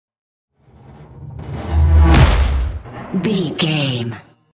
Whoosh to hit electronic
Sound Effects
dark
futuristic
intense
woosh to hit